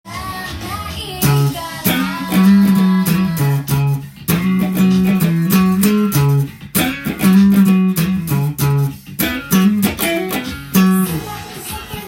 ベースソロをギターtab譜にしました
少し遅めで譜面通りに弾いてみました
ギターで弾く場合は、カッティング奏法で弾くと同じような
スケールはC♯マイナーペンタトニックスケールを使っているようで